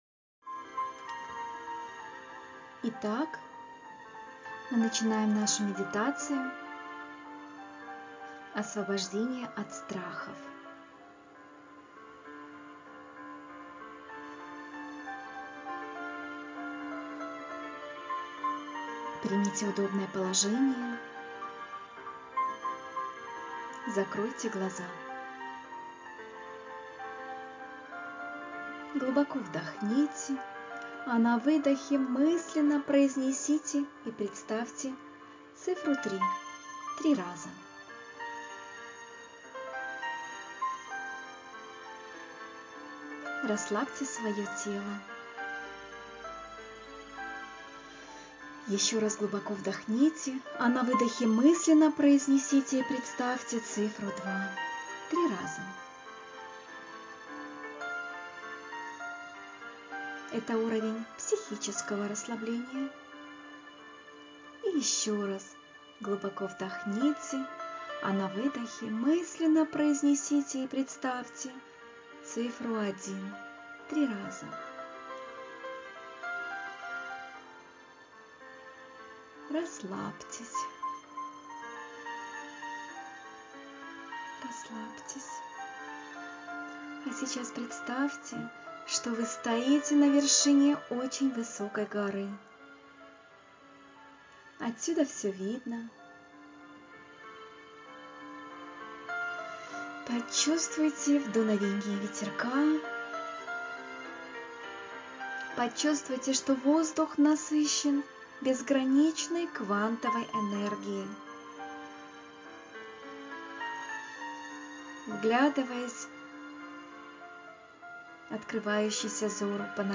Медитация "Освобождение от страхов" - Бизнесвизитка Монреаль